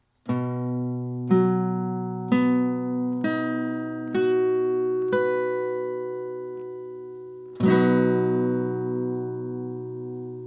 H-Dur-Akkord, Barre, E-Saite, Gitarre
H-Dur-Barre-E.mp3